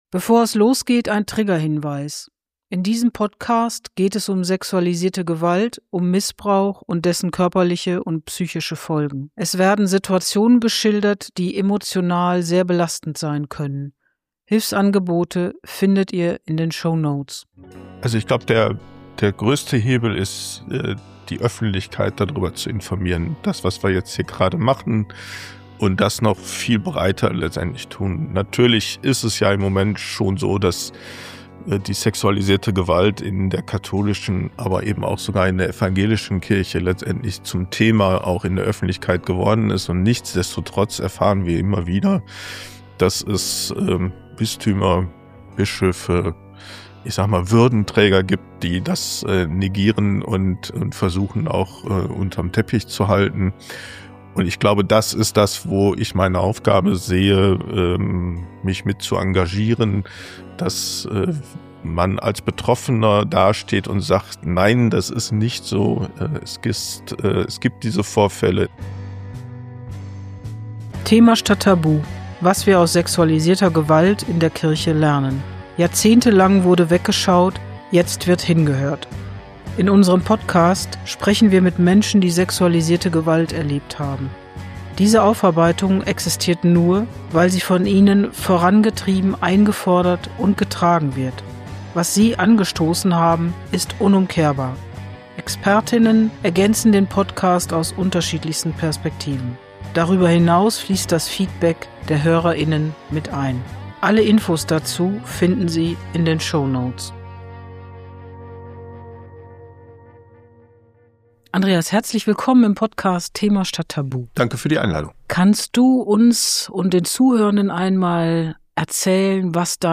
Er beschreibt innere Konflikte, das lange Schweigen und den Weg hin zu mehr Klarheit, Selbstverantwortung und einem neuen Umgang mit der eigenen Vergangenheit. Eine ruhige, ehrliche Episode über Verletzlichkeit, Verantwortung und den Mut, die eigene Geschichte sichtbar zu machen.